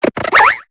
Cri de Colombeau dans Pokémon Épée et Bouclier.